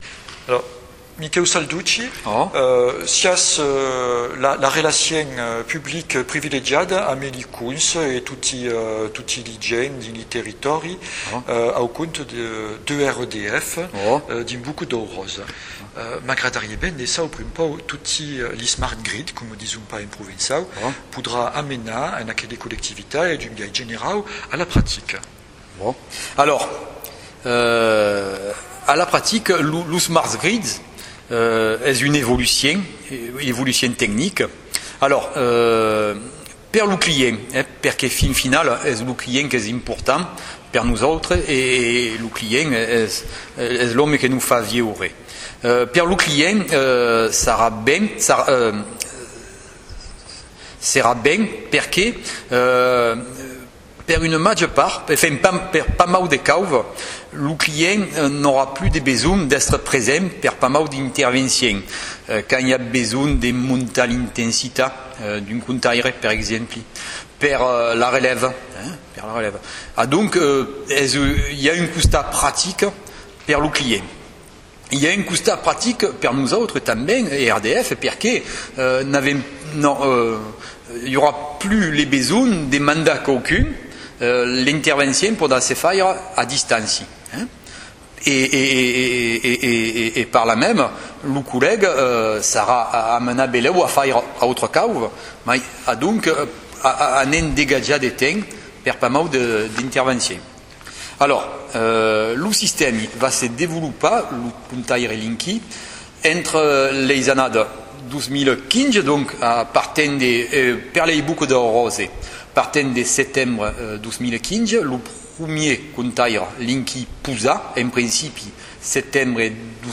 Leis avantatges de Linky - entrevista